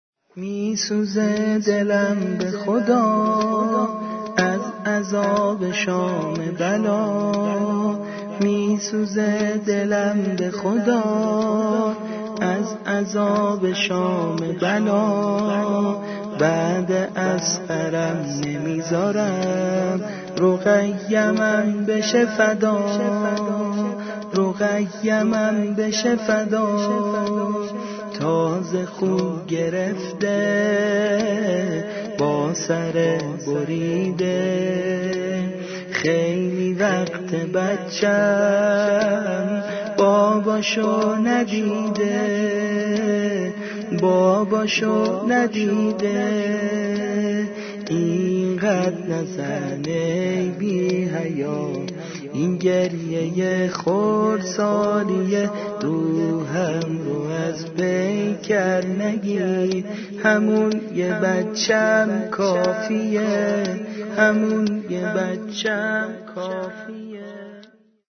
دانلود سبک